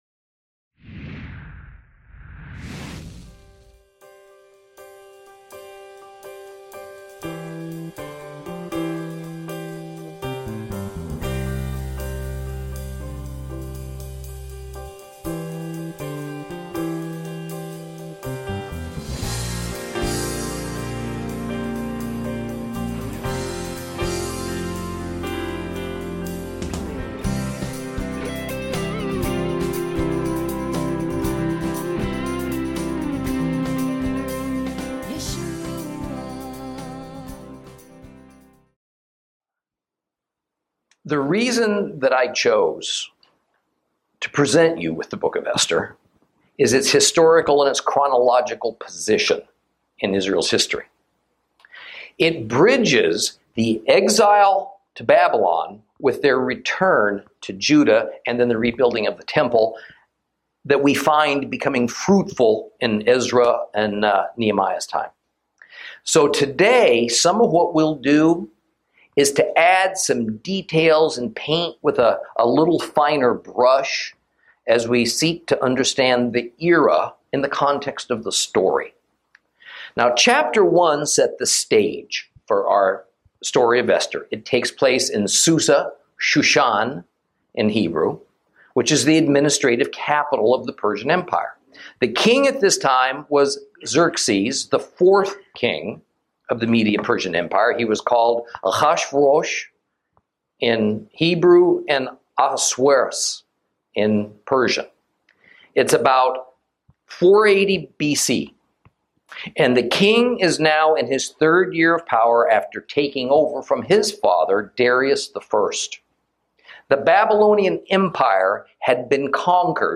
Lesson 3 Ch2 - Torah Class